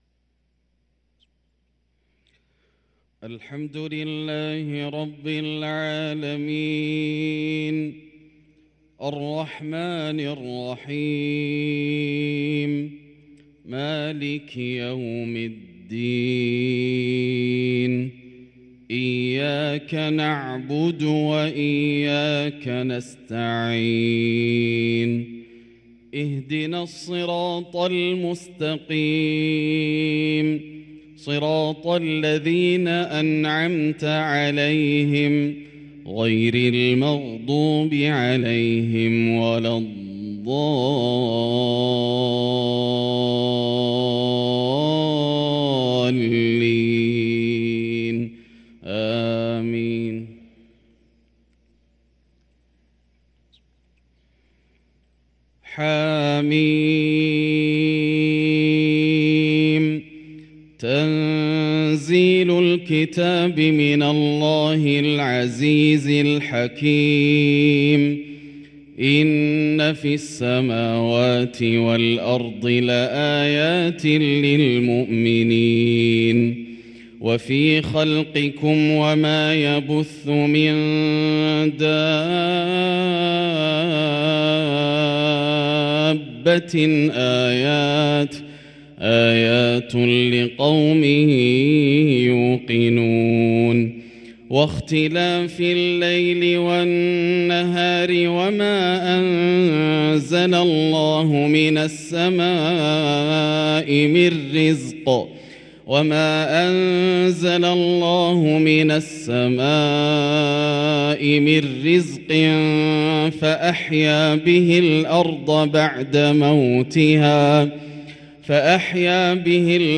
صلاة الفجر للقارئ ياسر الدوسري 22 شعبان 1444 هـ